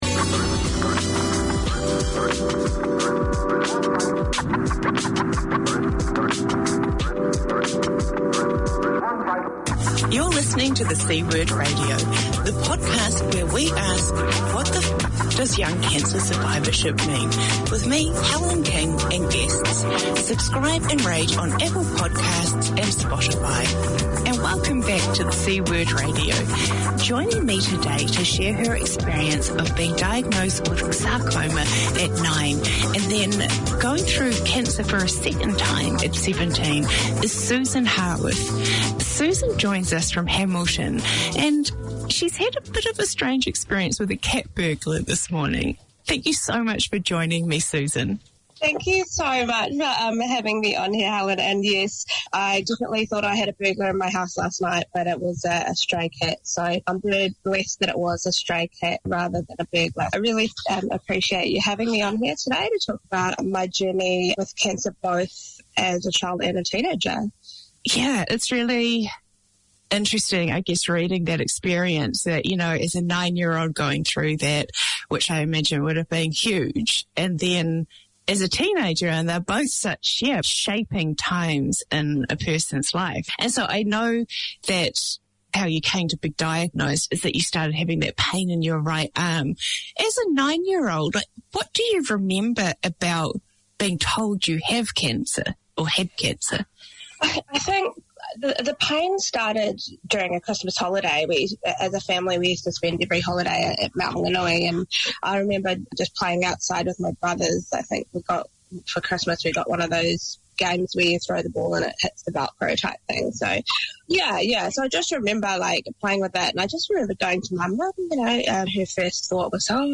Pasifika Wire 4:50pm SATURDAY Community magazine Language: English Pasifika Wire Live is a talanoa/chat show featuring people and topics of interest to Pasifika and the wider community.